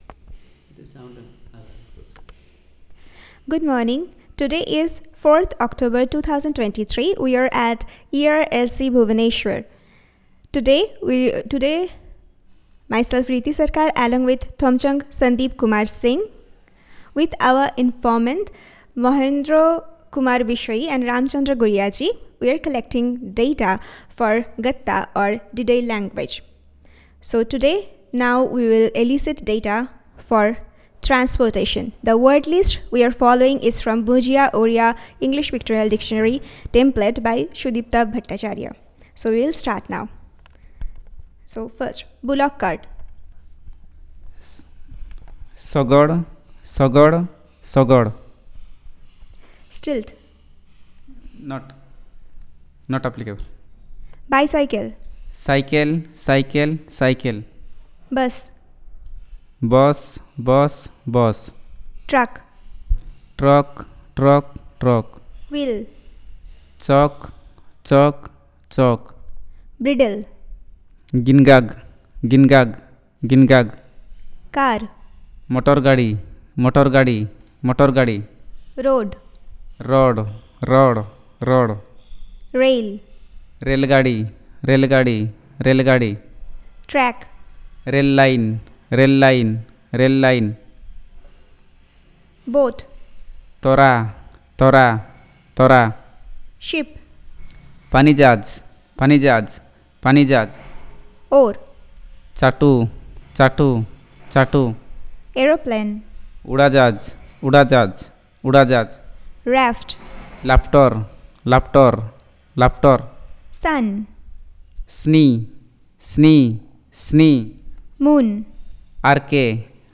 Elicitation of words on Transport and related